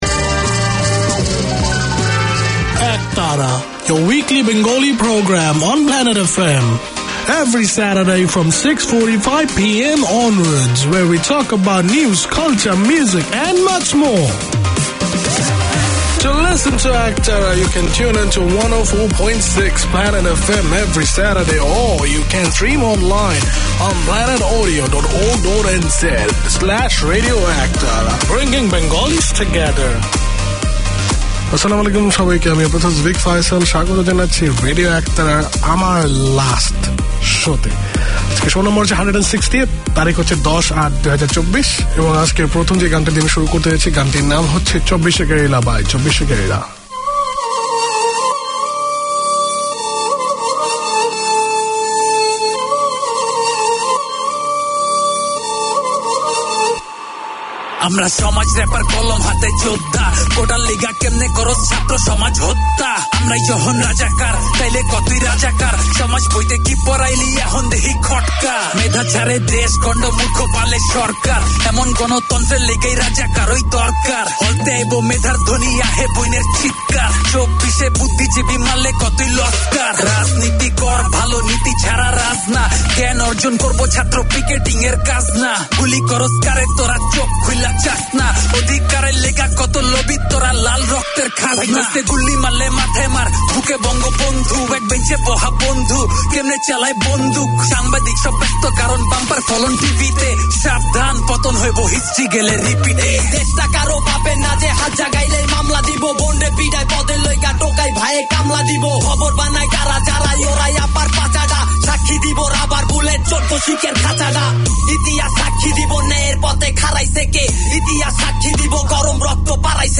Produced by and for the Bangladeshi community in Auckland, Radio Ektara features current affairs, community notices, music and interviews to entertain and inform.